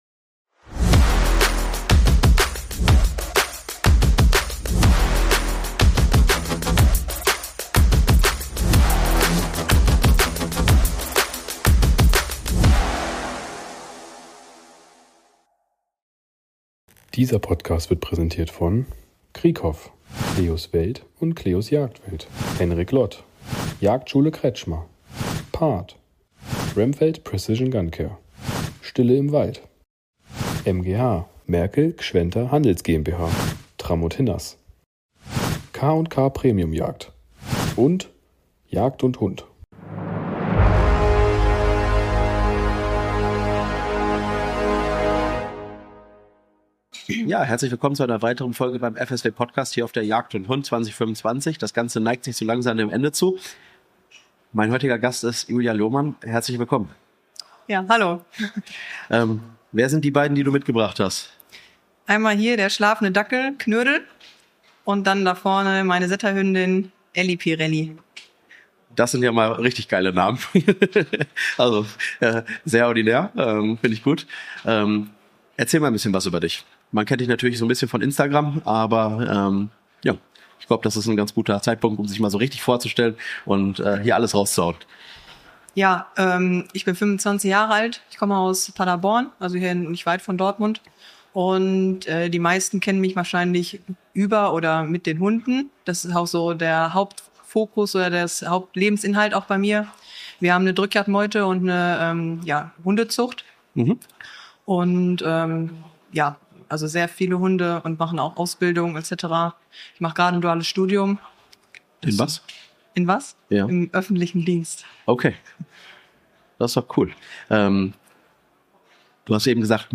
Willkommen auf der Jagd & Hund 2025! Wir sind live auf Europas größter Jagdmesse unterwegs und sprechen mit spannenden Gästen aus der Jagdszene.